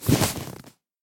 MinecraftConsoles / Minecraft.Client / Windows64Media / Sound / Minecraft / mob / horse / leather.ogg
leather.ogg